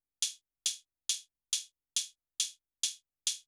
AND HH    -L.wav